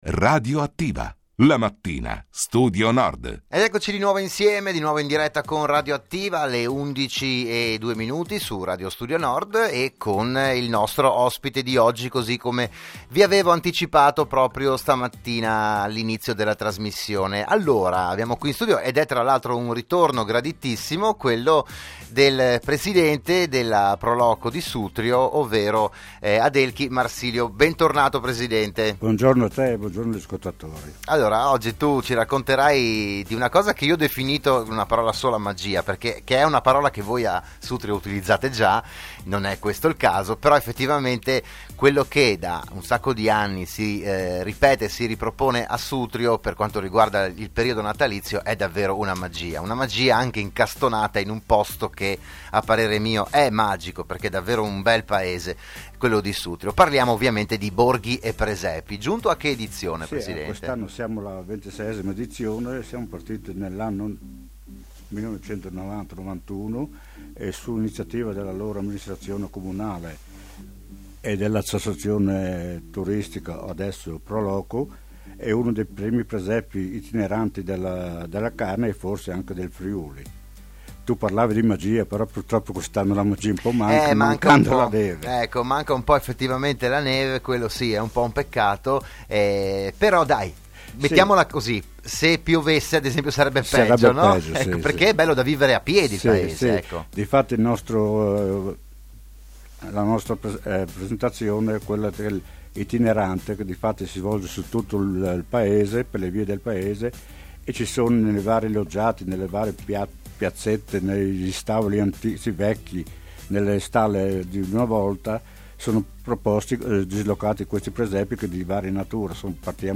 Il podcast dell'intervento a "RadioAttiva", la trasmissione di Radio Studio Nord